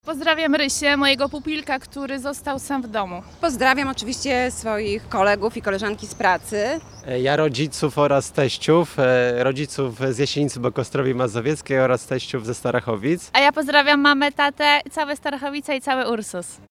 Na zakończenie rozmowy uczestnicy sondy pozdrowili swoich bliskich.